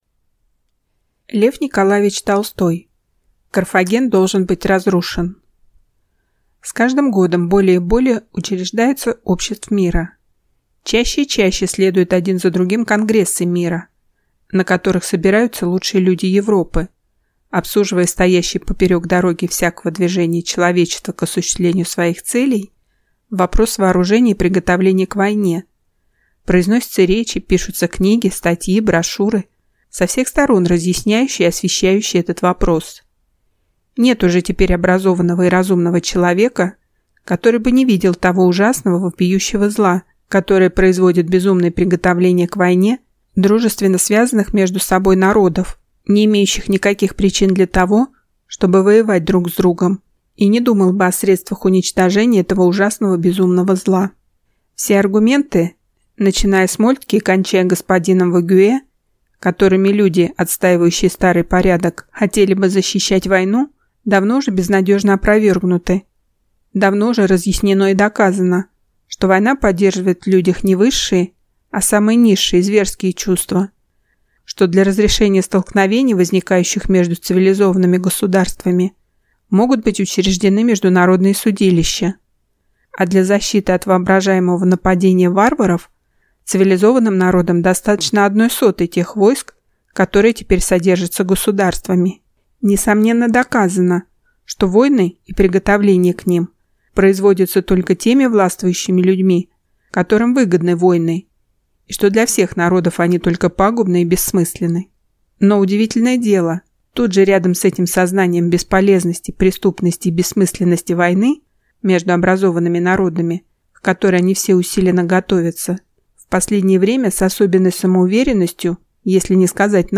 Аудиокнига Carthago delenda est (Карфаген должен быть разрушен) | Библиотека аудиокниг